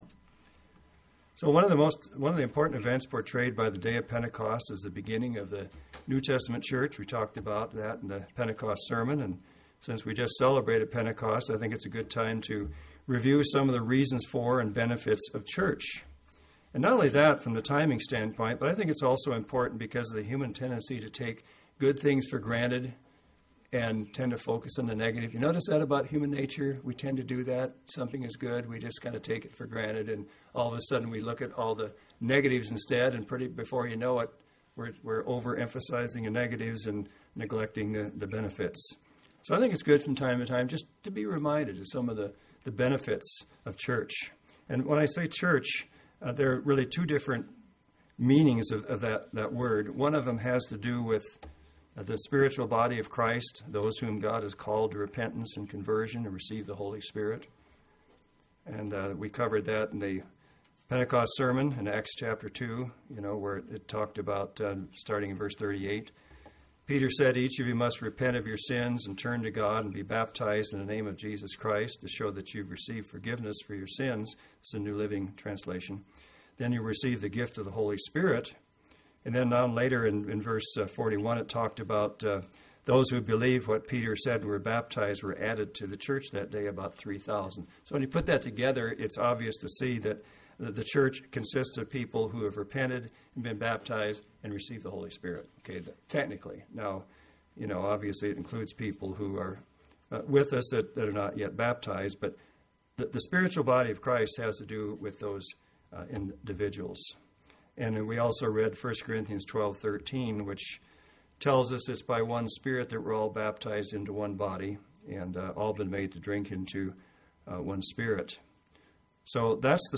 UCG Sermon Notes Benefits of the Church Bend 6-11 Medford 6-18 Introduction – One of the important events portrayed by the Day of Pentecost is the beginning of the NT Church.